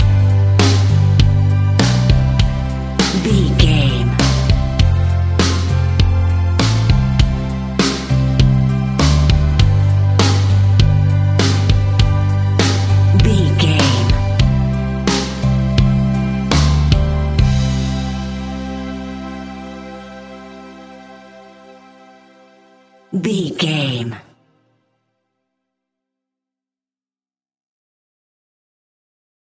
Ionian/Major
Slow
melancholic
smooth
uplifting
electric guitar
bass guitar
drums
indie pop
organ